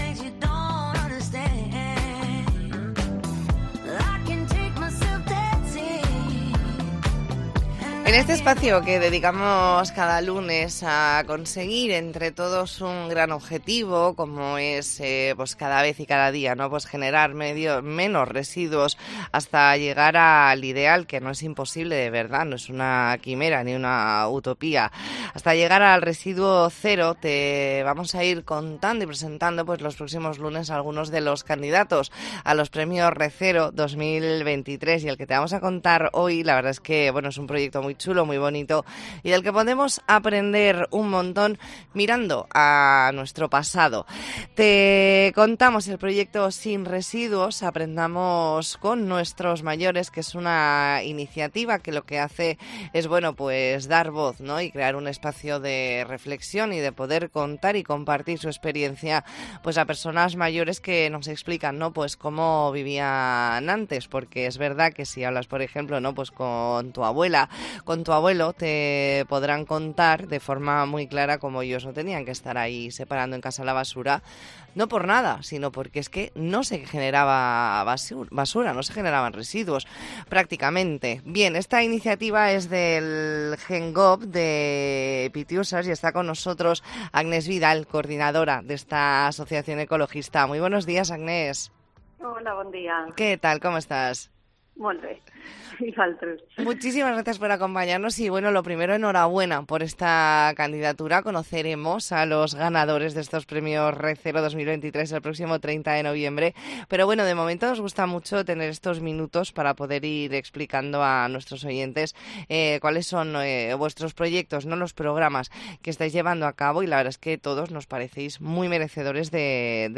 Entrevista en La Mañana en COPE Más Mallorca, lunes 13 de noviembre de 2023.